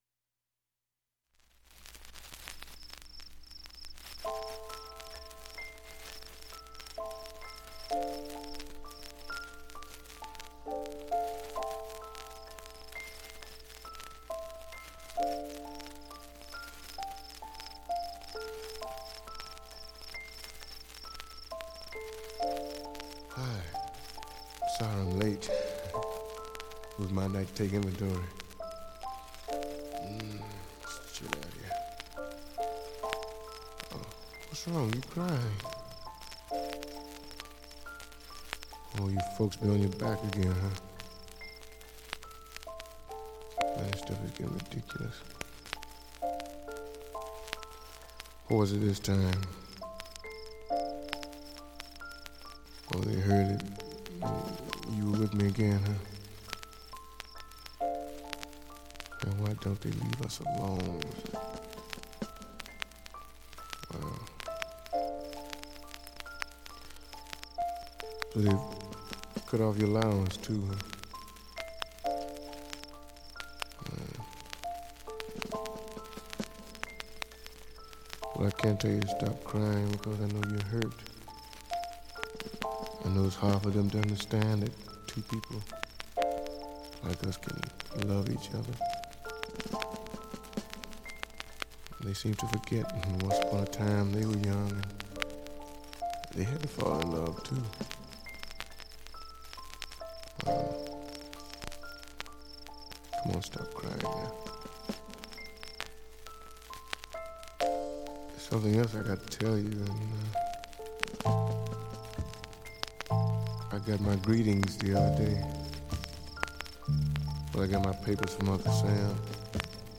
全体にバックチリ出ますが
演奏中は問題の無いレベルです。
下記プツもあまりストレスはありません。
薄い白いシミでかすかなサーフェスチリ、
その間に小さいスレでプツ出ます。